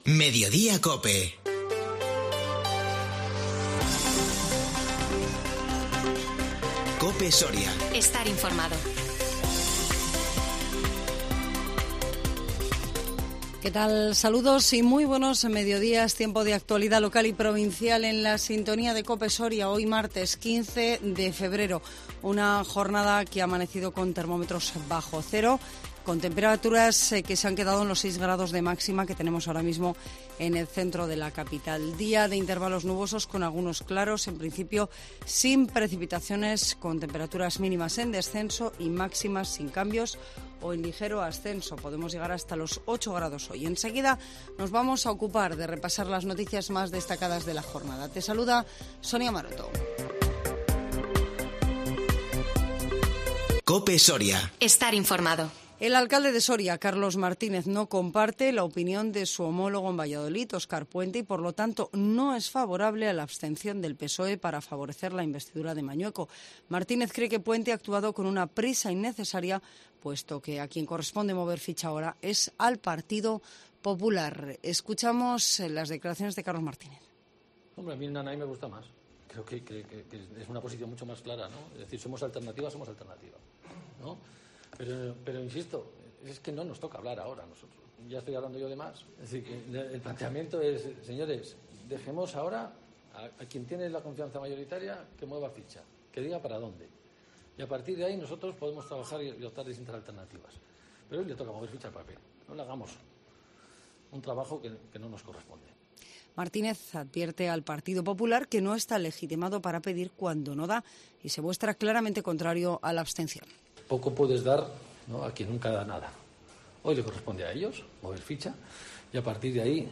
INFORMATIVO MEDIODÍA COPE SORIA 15 FEBRERO 2022